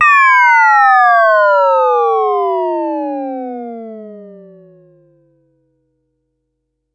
Index of /musicradar/essential-drumkit-samples/Vermona DRM1 Kit
Vermona Fx 01.wav